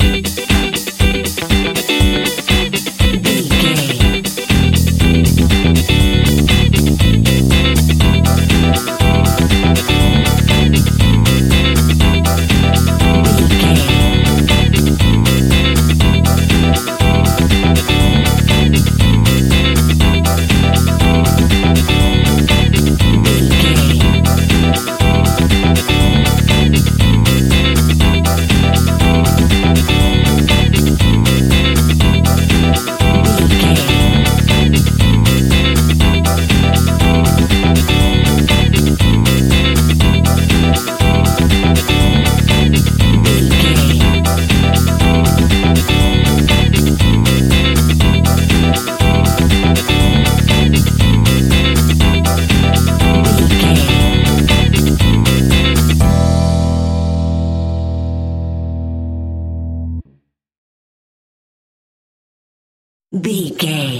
Aeolian/Minor
groovy
uplifting
energetic
drums
bass guitar
electric piano
synthesiser
electric guitar
brass
disco house
electronic funk
upbeat
synth leads
Synth Pads
synth bass
drum machines